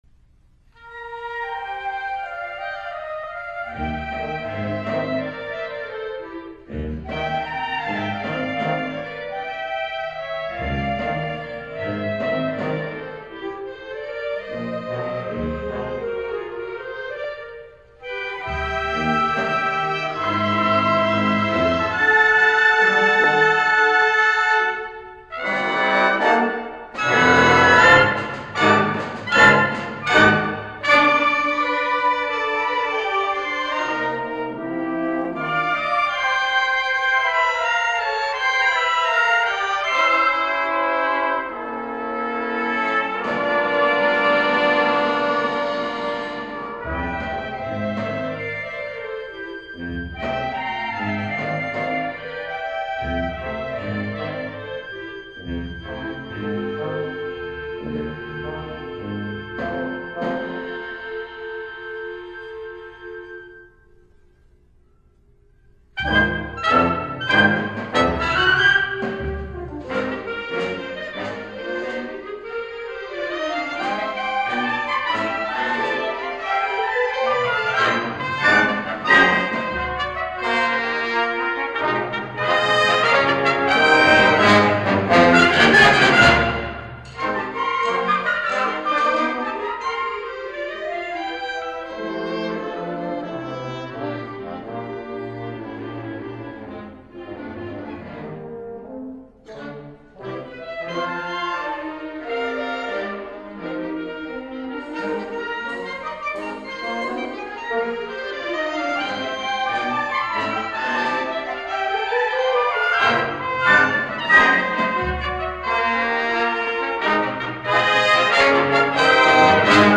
1965 Band
Serenade for Band